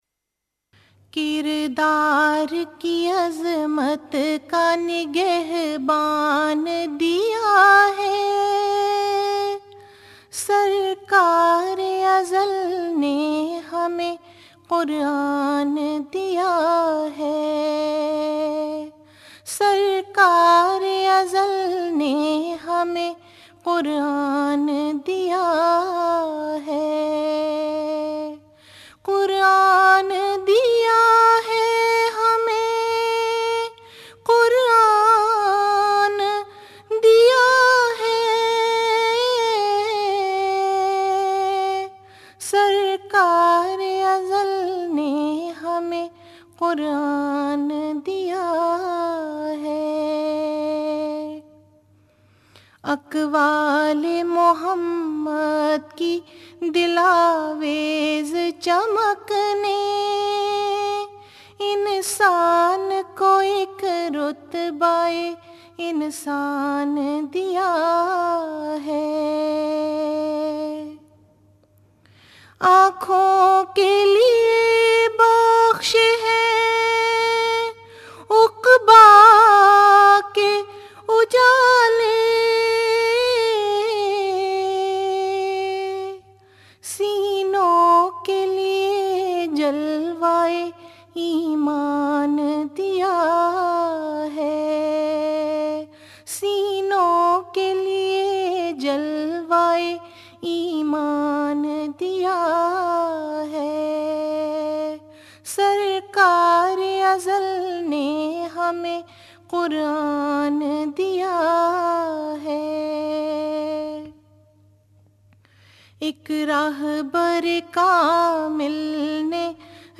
نظمیں (Urdu Poems)